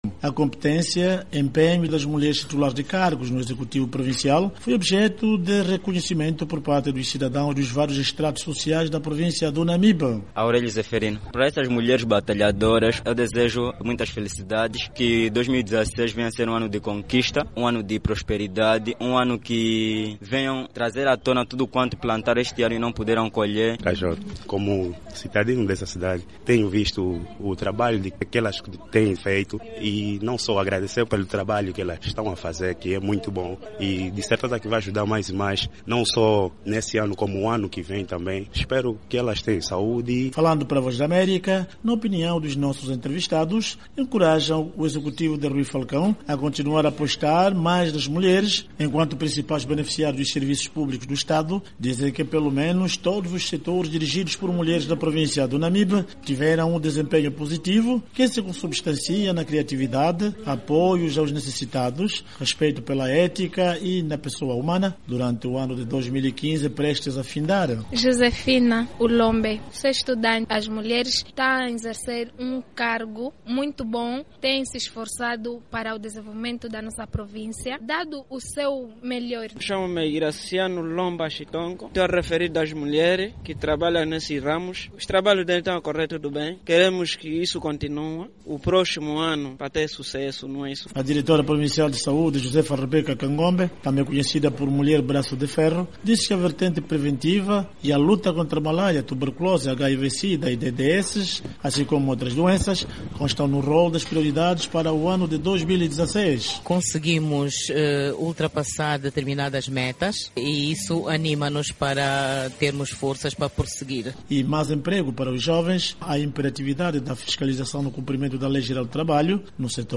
Em declarações à VOA, os populares encorajam as autoridades a apostar mais nas mulheres em cargos de liderança por terem demonstrado um lato grau de responsabilidade, criatividade, atenção e apoios prestados aos necessitados, assim como o respeito pela ética e a pessoa humana,.